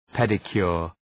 Προφορά
{‘pedə,kjʋr}
pedicure.mp3